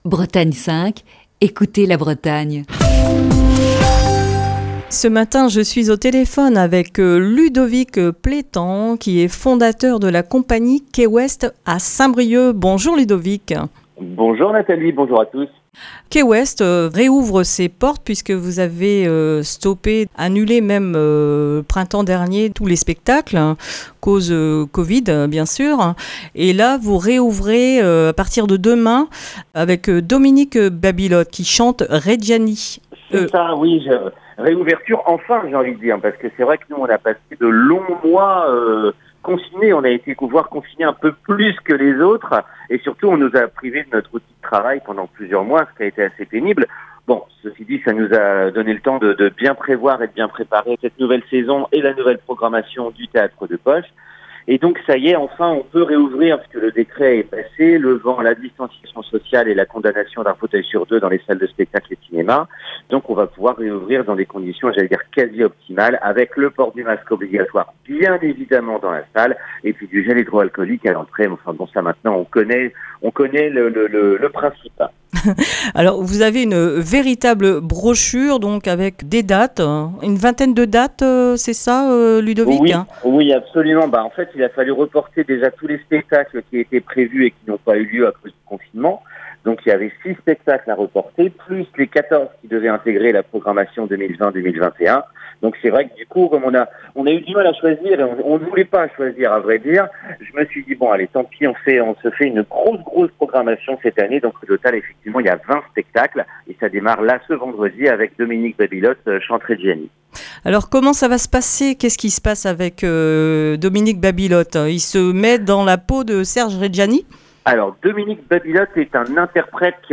Émission du 24 septembre 2020.